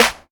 Snares
DJ Paul Snare 2.wav